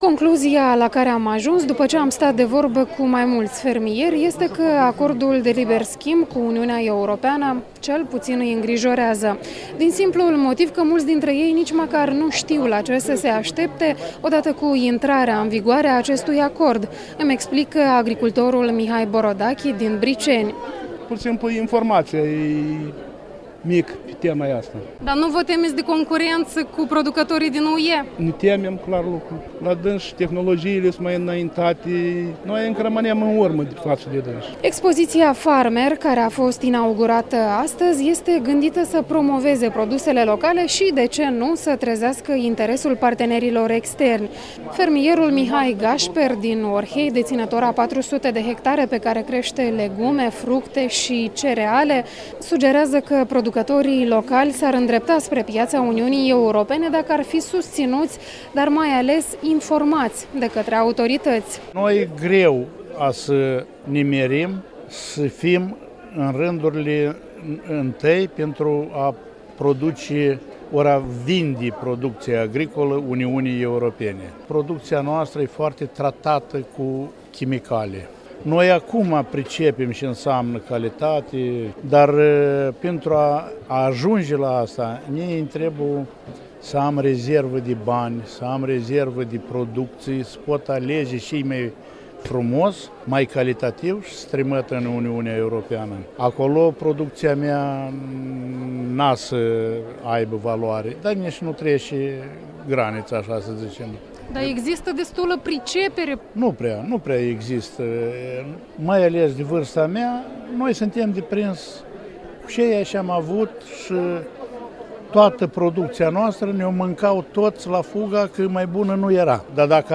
De vorbă cu fermieri, la expoziţia "Farmer 2013"